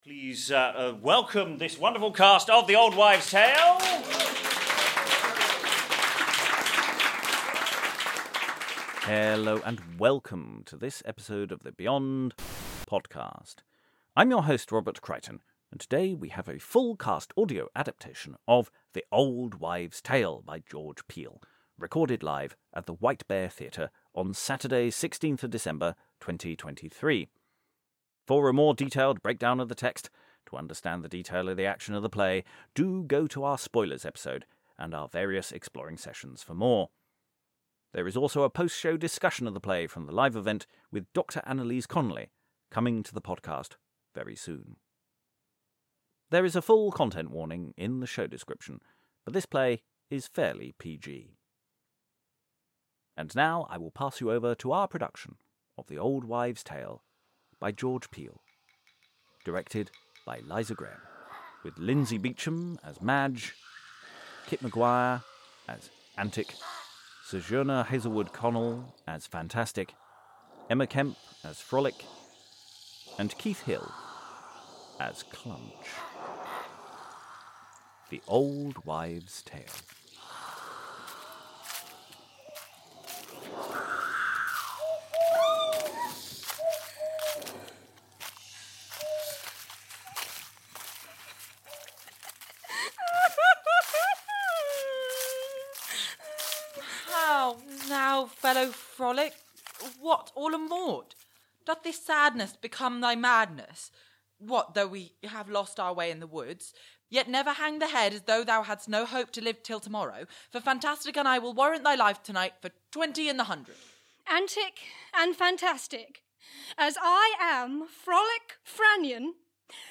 Full cast audio time of this fantasy tale...
Recorded Live at the White Bear Theatre, we present our full cast audio adaptation of George Peele's The Old Wives' Tale.
Other parts played by members of the company